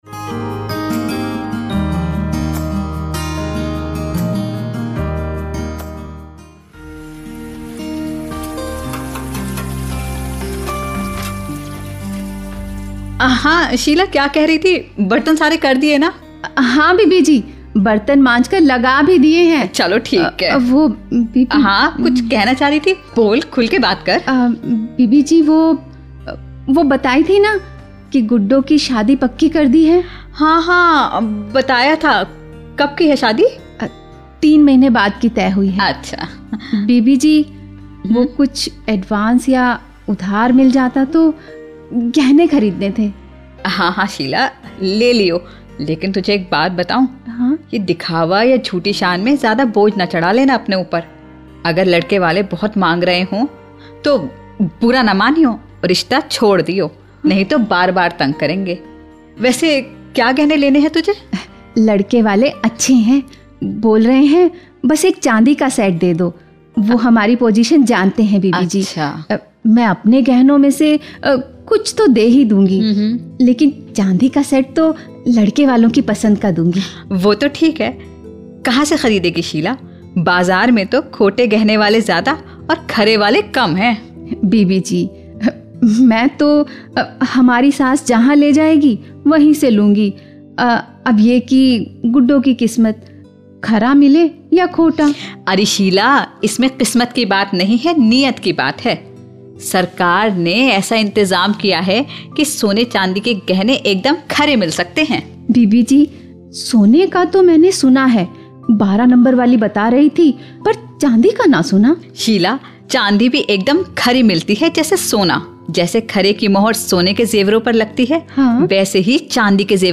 Skit on promotion of Hallmark.